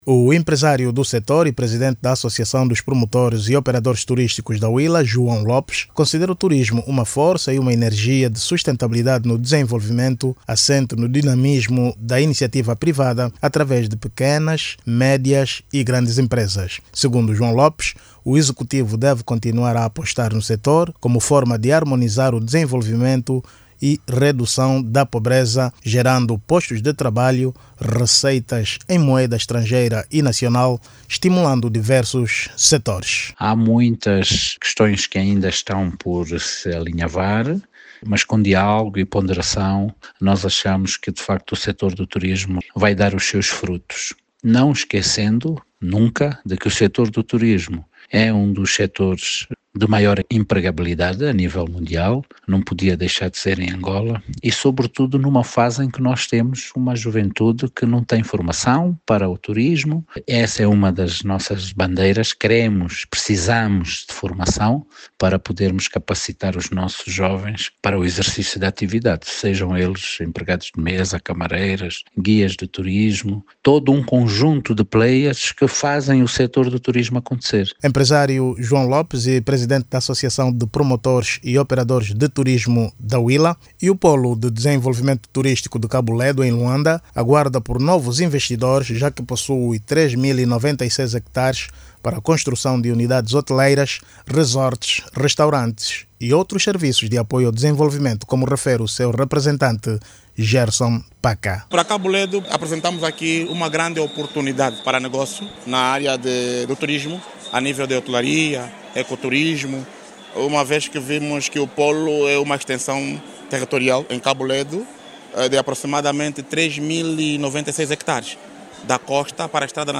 Esta posição foi defendida à margem da Bolsa Internacional de Turismo de Angola, BITUR ANGOLA 2025, que decorre no Centro de Convenções de Talatona, em Luanda.